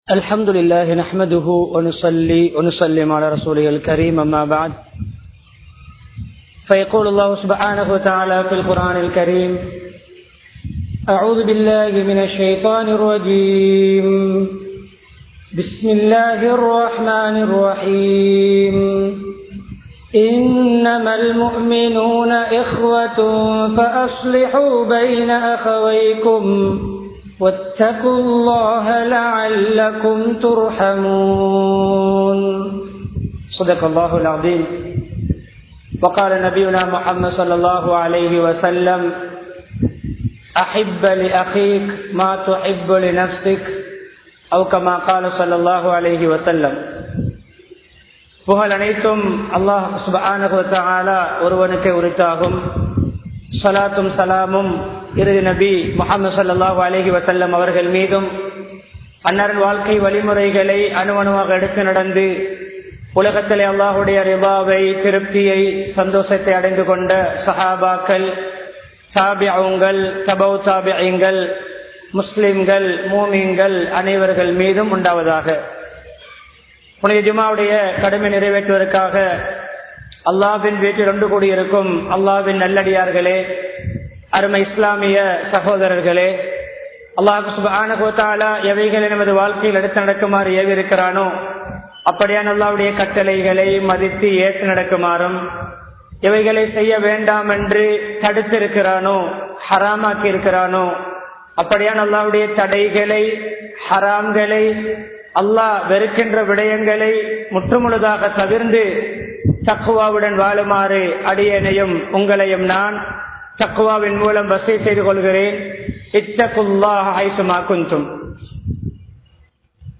சகோதரத்தைப் பேணுவோம் | Audio Bayans | All Ceylon Muslim Youth Community | Addalaichenai
Hanwella Jumua Masjidh